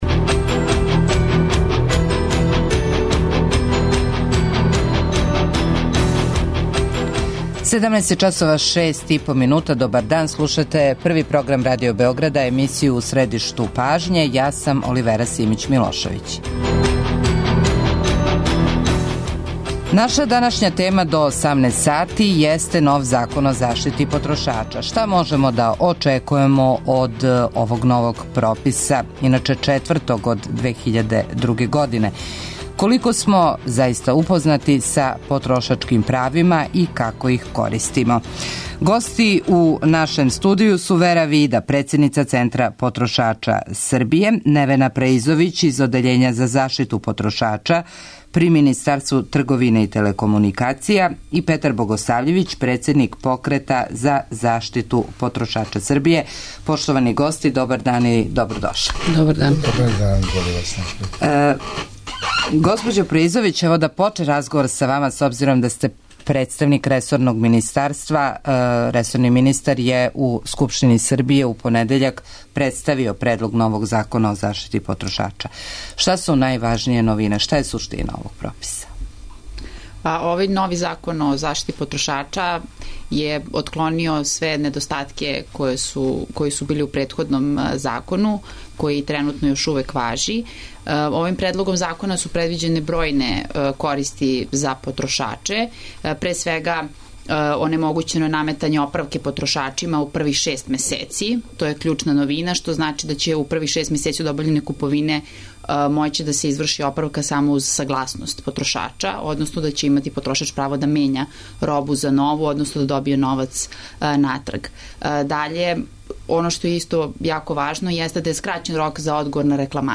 Уколико сматрате да су ваша потрошачка права нарушена и не знате коме да се обратите - јавите се и поставите питање нашим гостима.